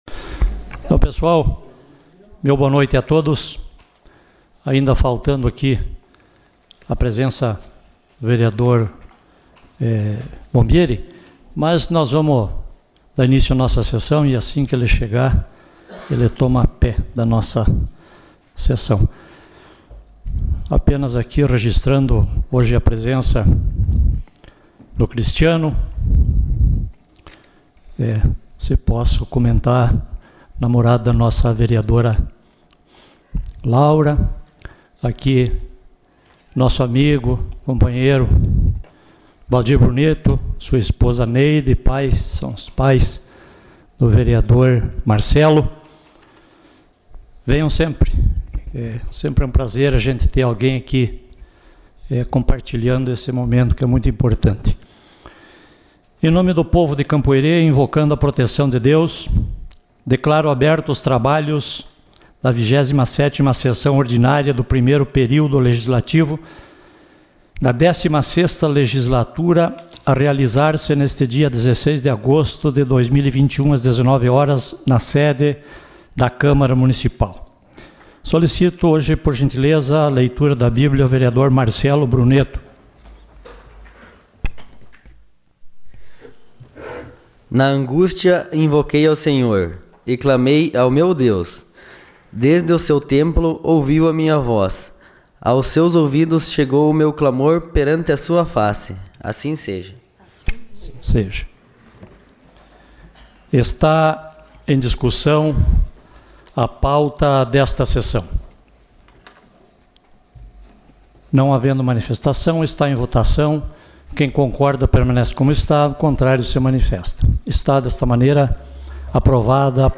Sessão Ordinária dia 16 de agosto de 2021.